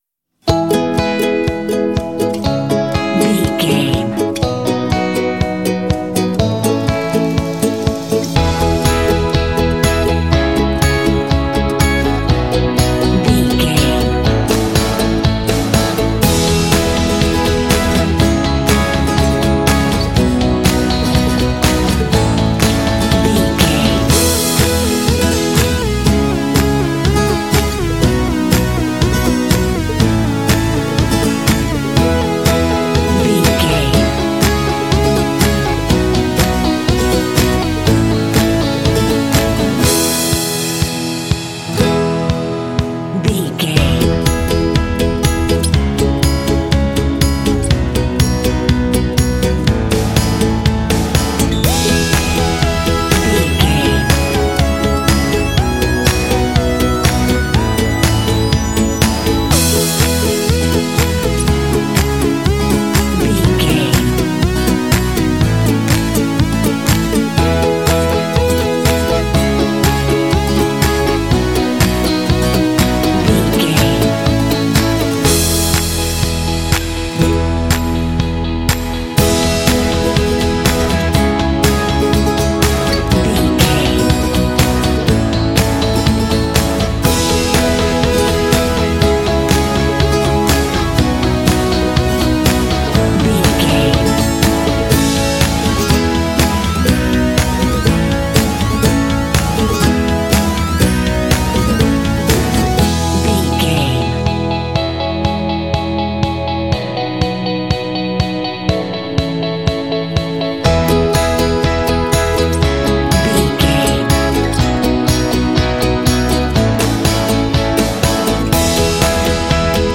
Ionian/Major
bouncy
happy
groovy
acoustic guitar
piano
drums
synthesiser
contemporary underscore